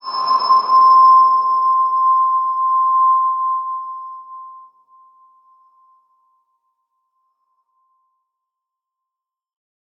X_BasicBells-C4-pp.wav